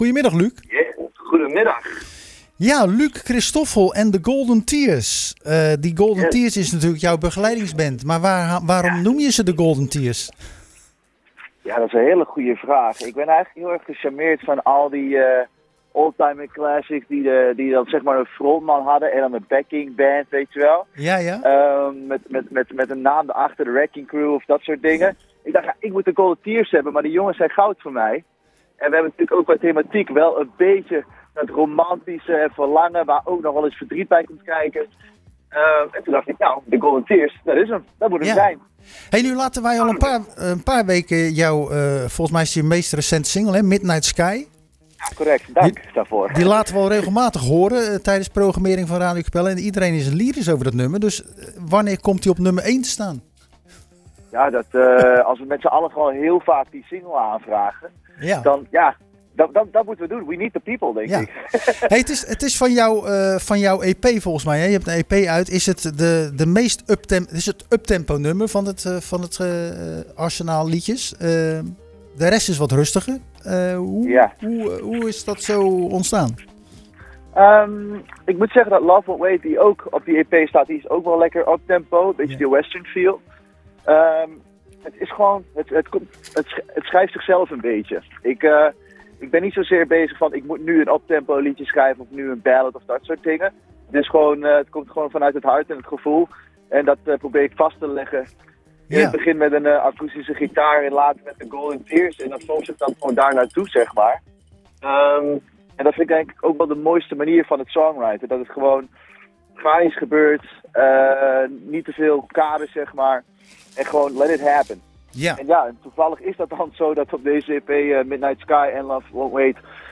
Gedurende de jaarlijkse Popronde bellen we tijdens het programma Zwaardvis wekelijks ��n van de deelnemers.